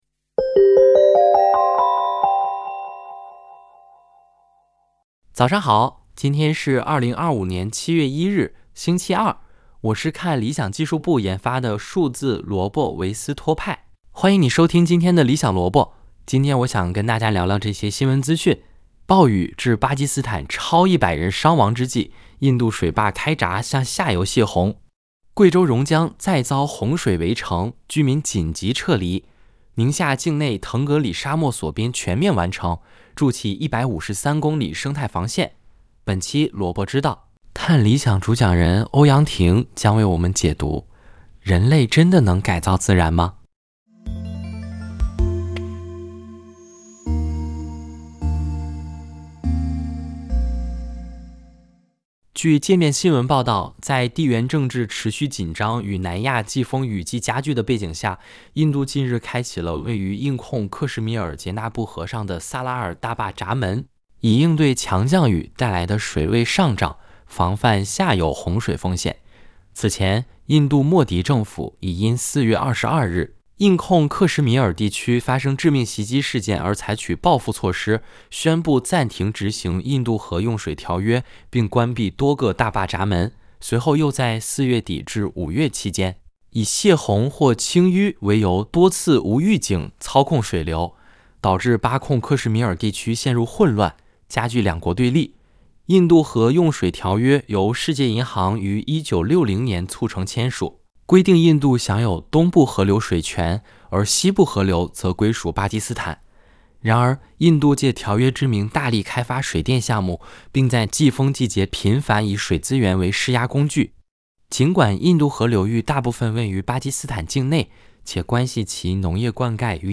《理想萝卜》是由看理想技术部研发的数字萝卜维斯托派主持的资讯节目。